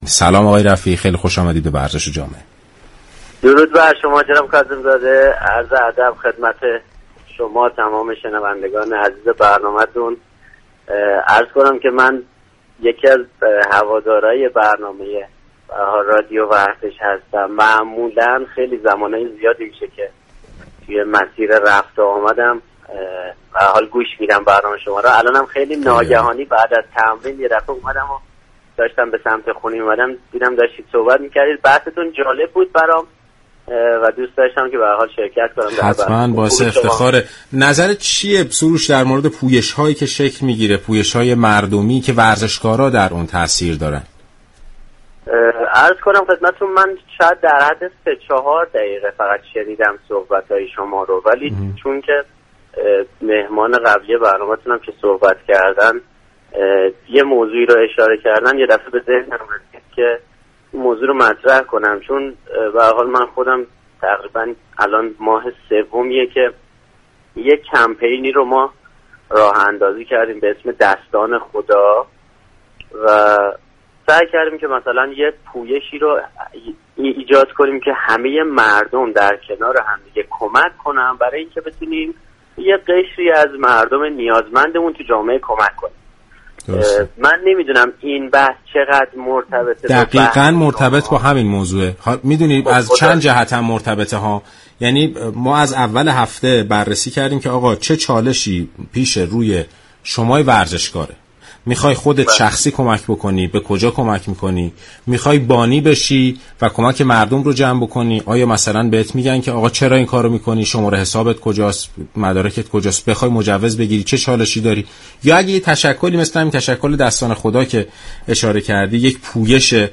شما می توانید از طریق فایل صوتی پیوست شنونده بخشی از برنامه "ورزش و جامعه" كه شامل صحبت های كامل سروش رفیعی است؛ باشید.